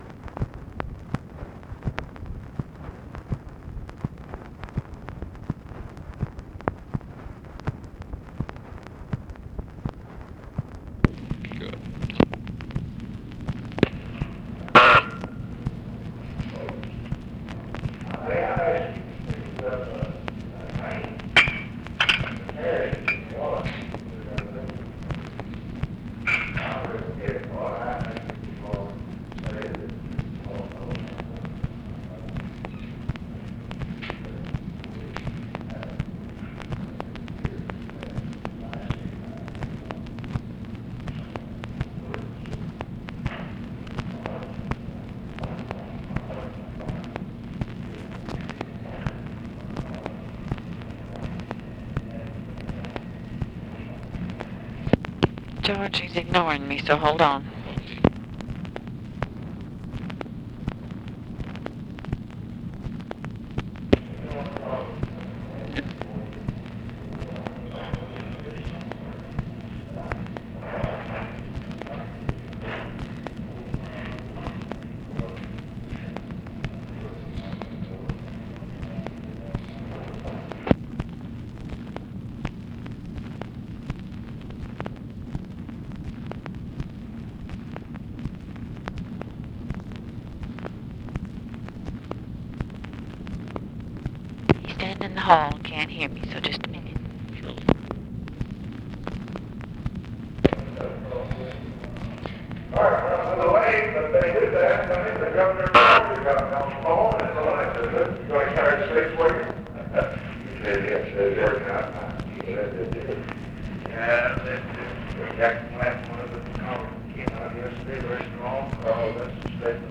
Conversation with GEORGE REEDY and OFFICE CONVERSATION, August 13, 1964
Secret White House Tapes